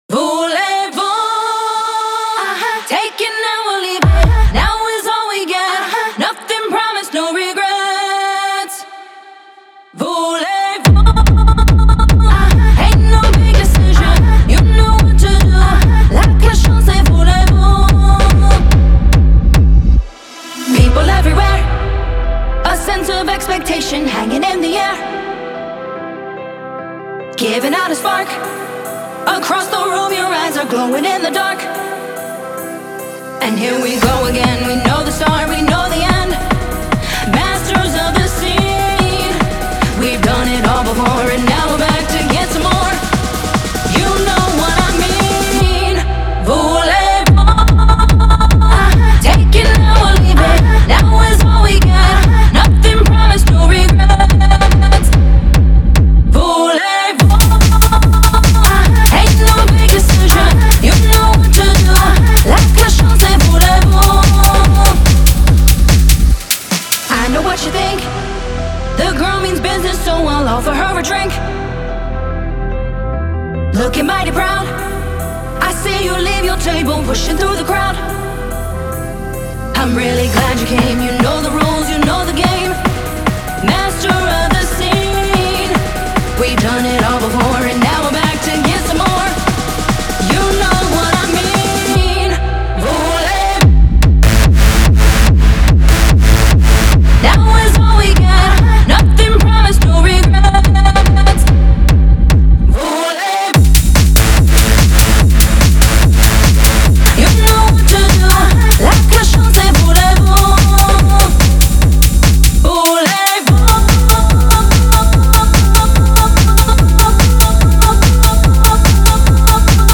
• Жанр: Electronic, Techno